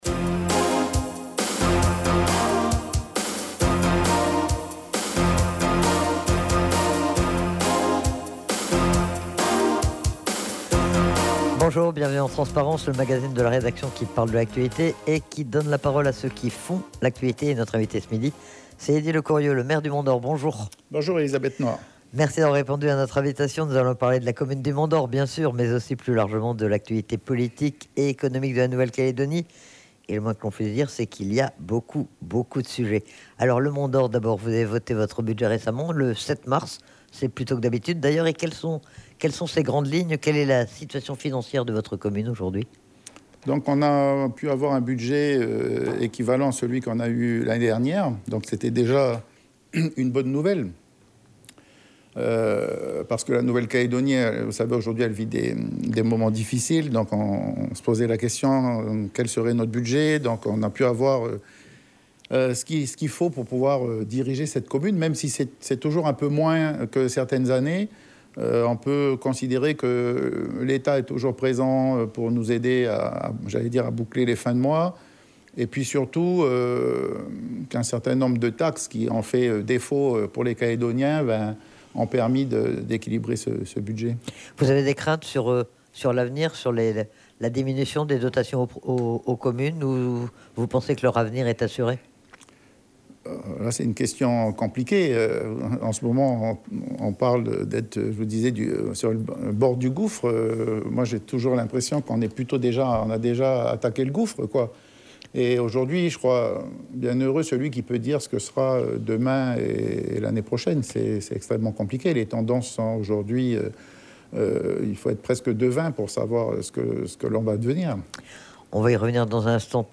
reçoit le maire du Mont Dore Eddie Lecourieux.